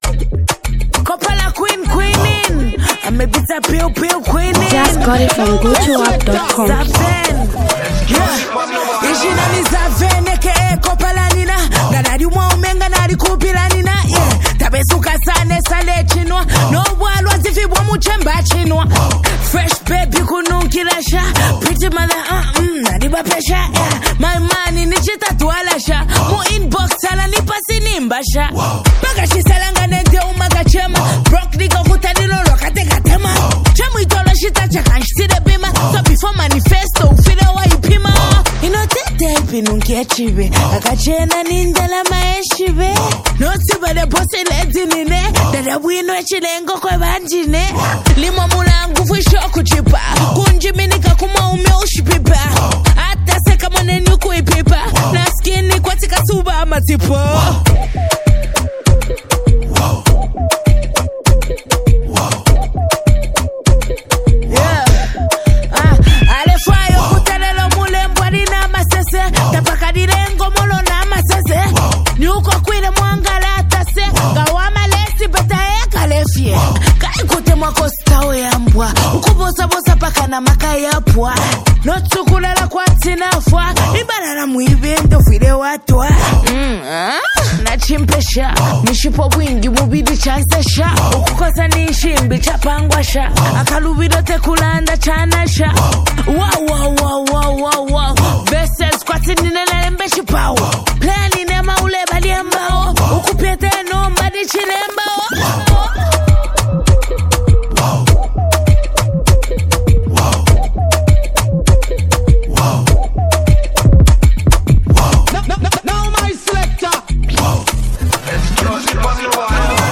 a bold declaration of her rap prowess.
Gritty bars and hard-hitting punchlines ignite social media